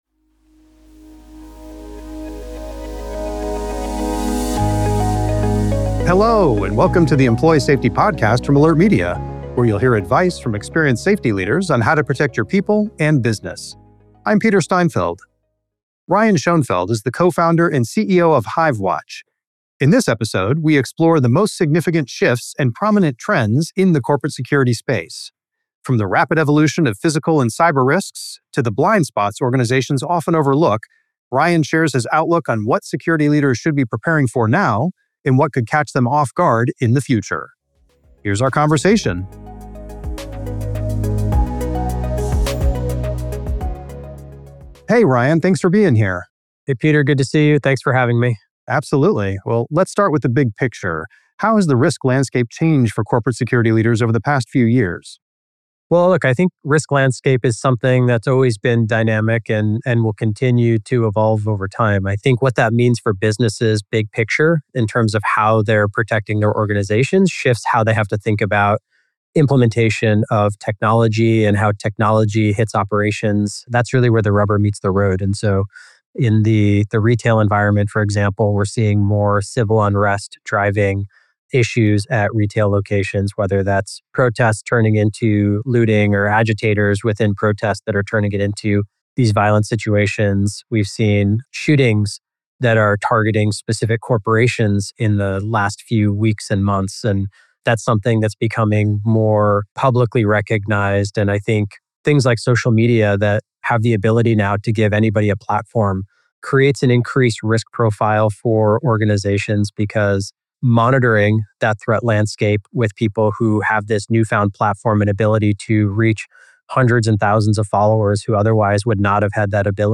You can find this interview and many more by following The Employee Safety Podcast on Spotify or Apple Podcasts .